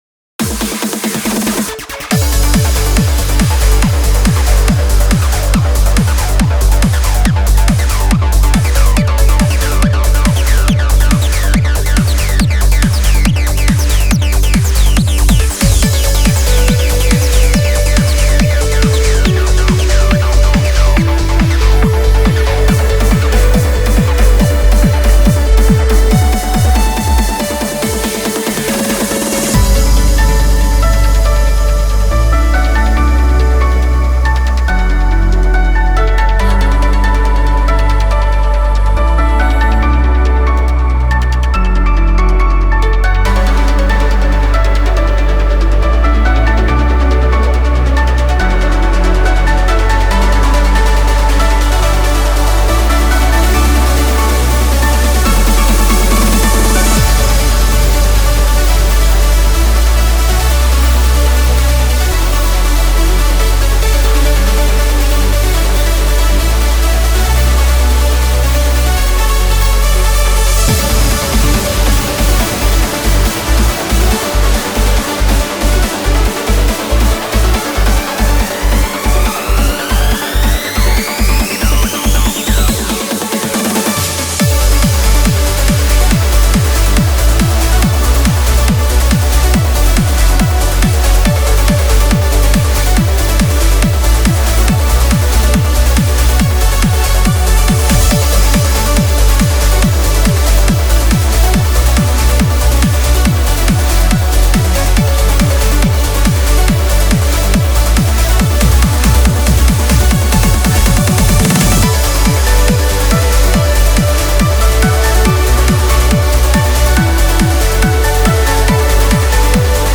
BPM140
Audio QualityPerfect (High Quality)
Commentaires[UPLIFTING TRANCE]